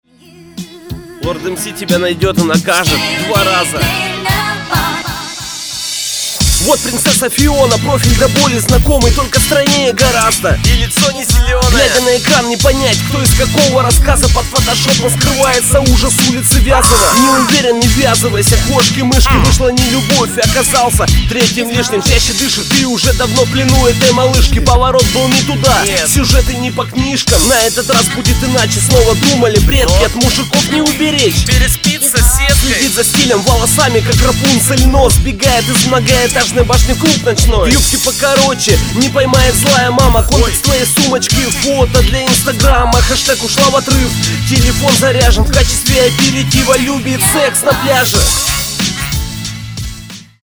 Оригинальная история, подал приятно.